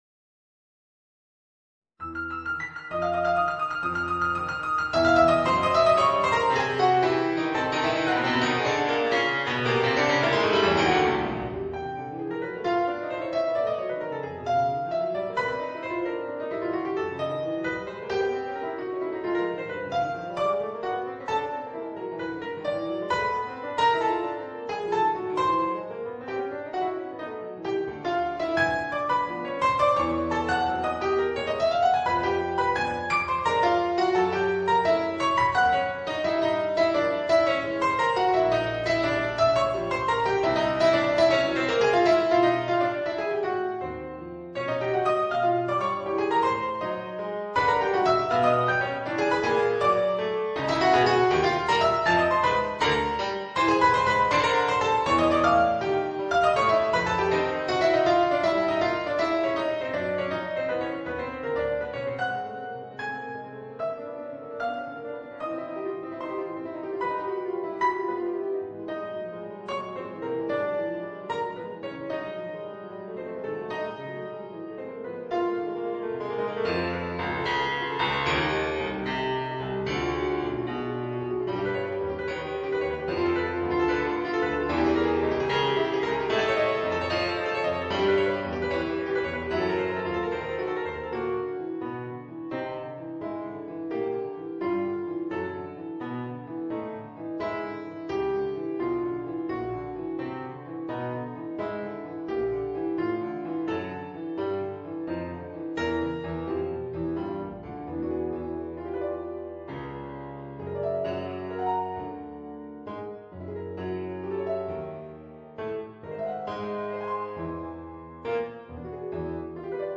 Voicing: Piano Solo